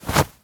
foley_object_throw_move_02.wav